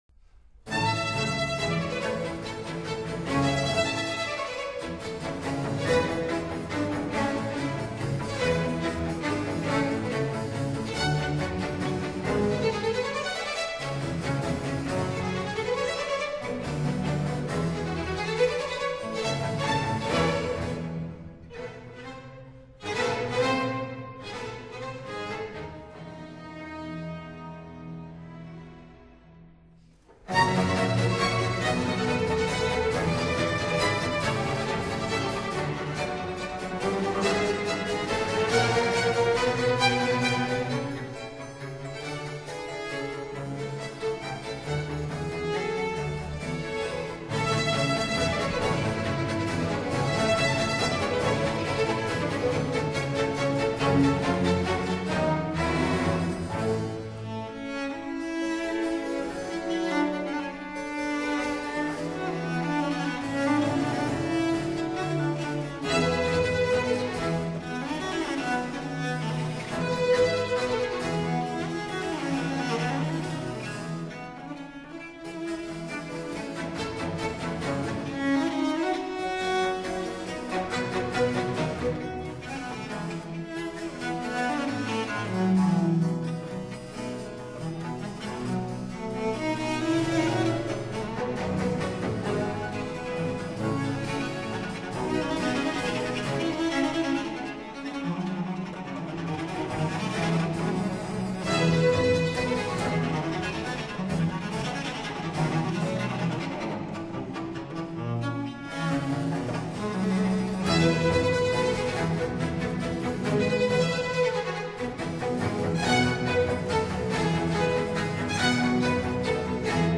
Allegro assai    [0:06:18.06]